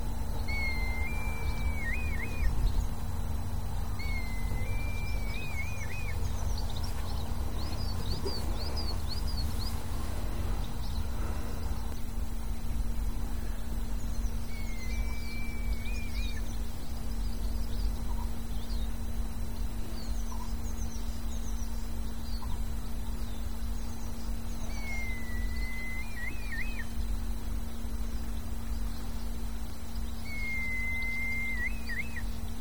Rode Wouw